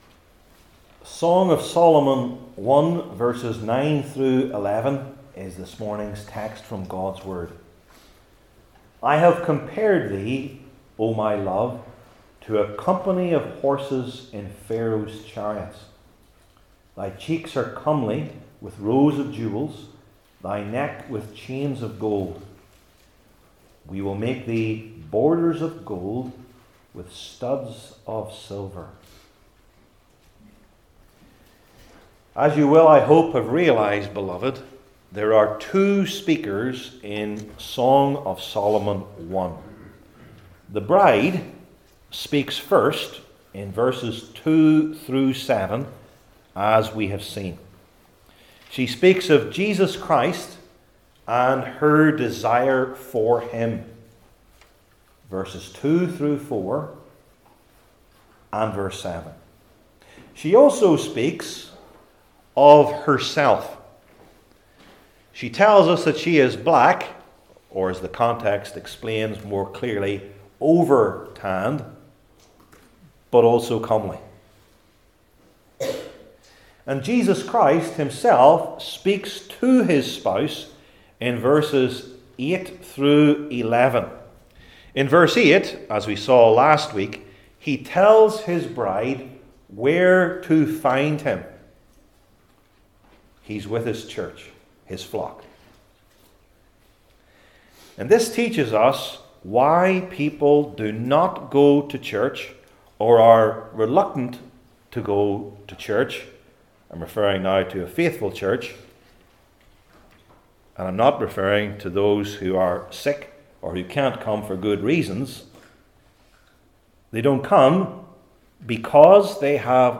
Song of Solomon 1:9-11 Service Type: Old Testament Sermon Series I. The Striking Comparison II.